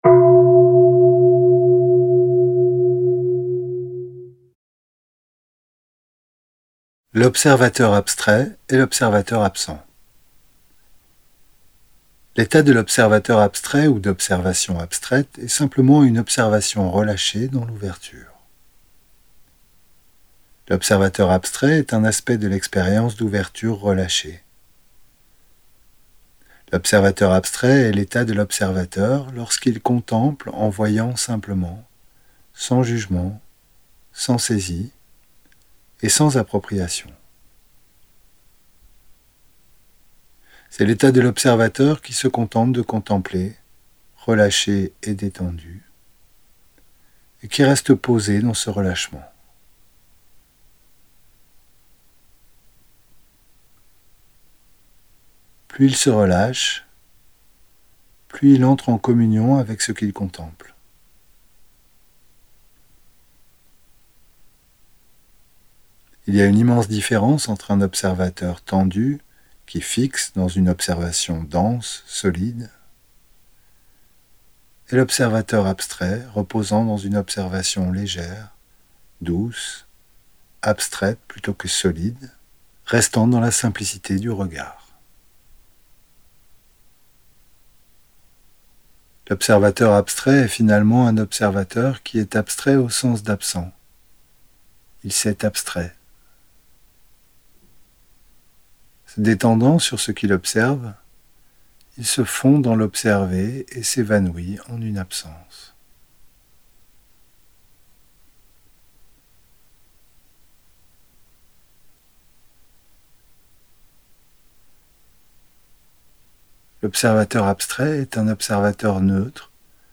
Audio femme